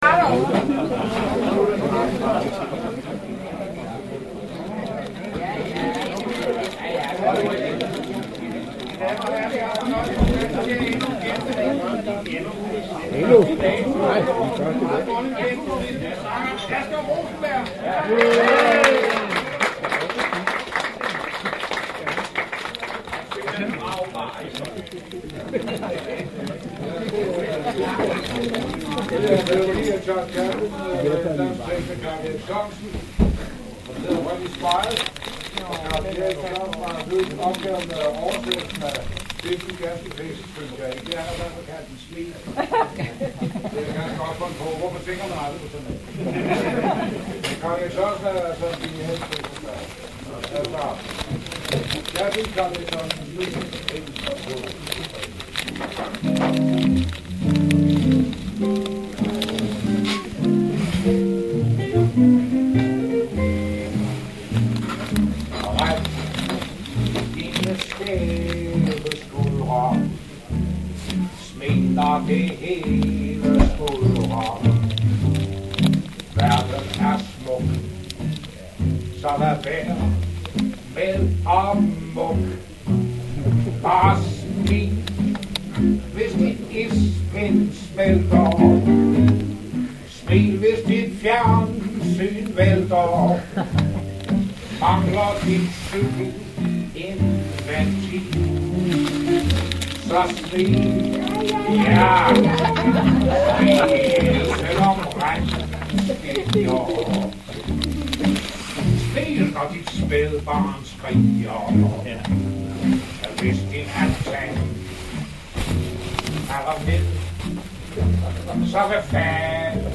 flügelhorn